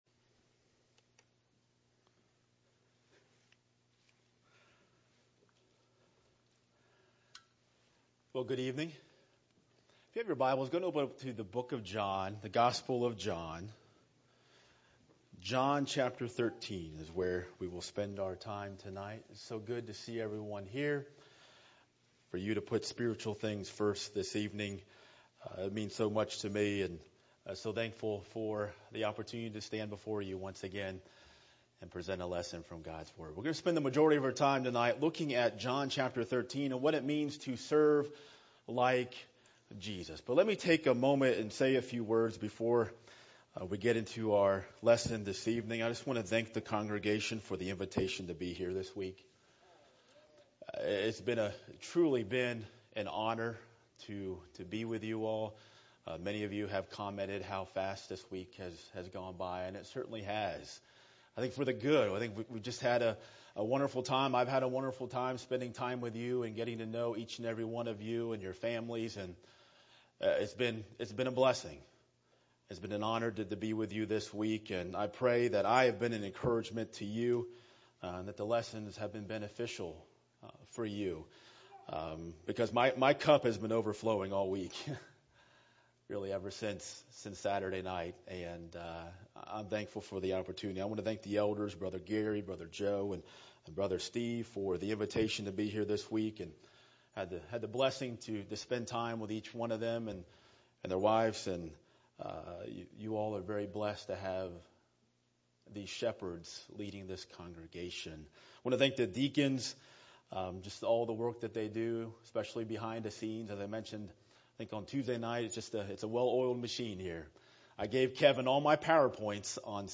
Weekly Sermons ← Newer Sermon Older Sermon →